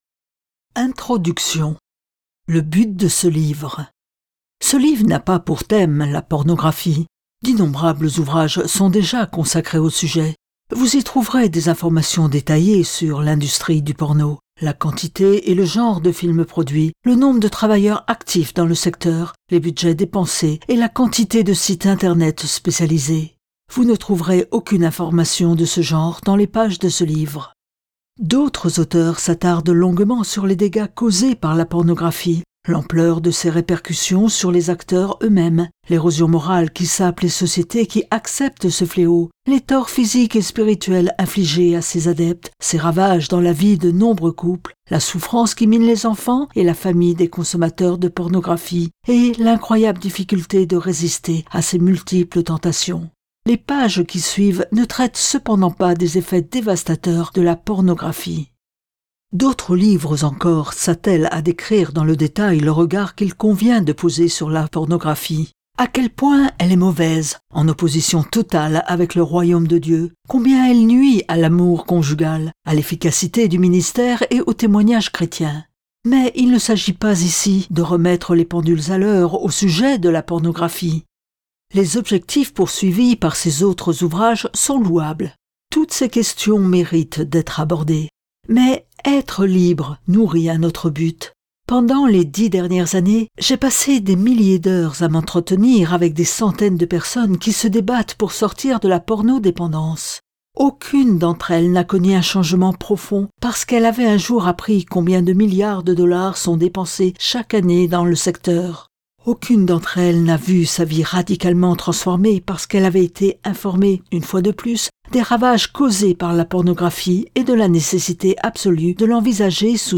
Click for an excerpt - Être libre, c'est possible ! de Heath Lambert